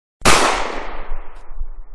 shot.mp3